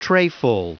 Prononciation du mot trayful en anglais (fichier audio)
Prononciation du mot : trayful